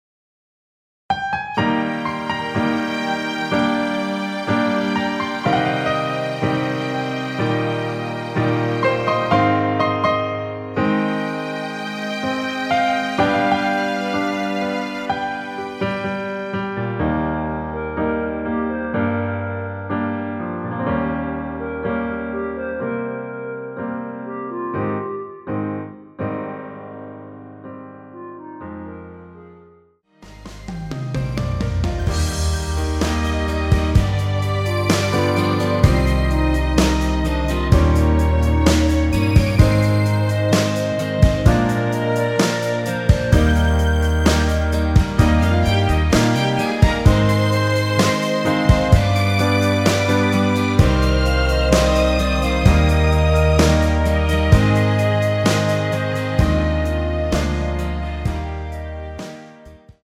원키에서(-2)내린 멜로디 포함된 MR입니다.
Eb
앞부분30초, 뒷부분30초씩 편집해서 올려 드리고 있습니다.
중간에 음이 끈어지고 다시 나오는 이유는